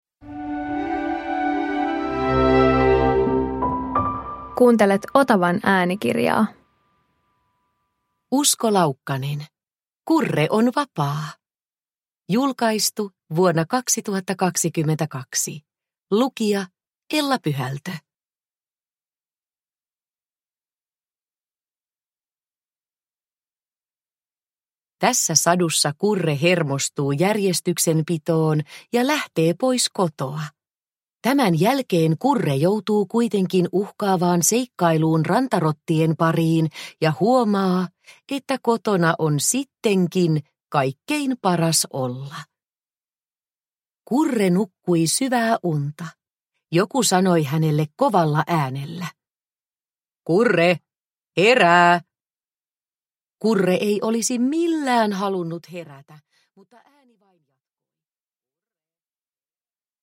Kurre on vapaa – Ljudbok – Laddas ner